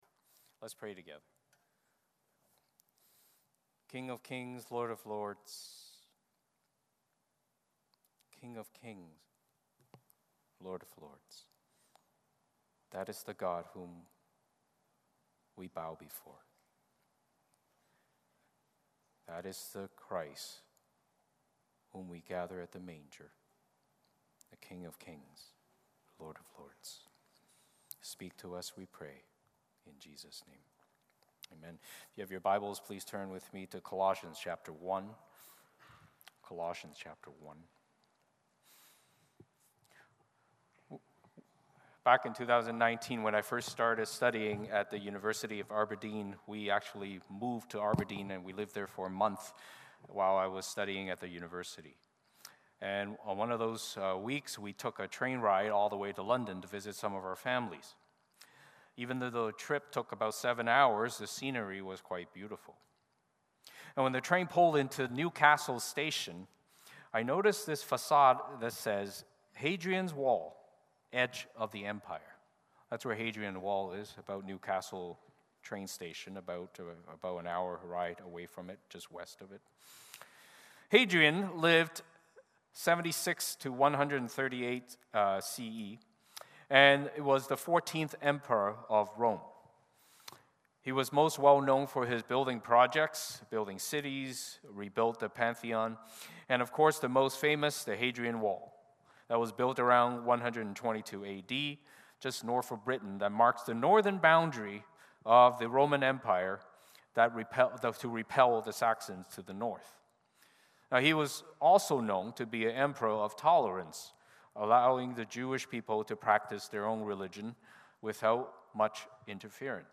Service Type: Sunday Morning Service Passage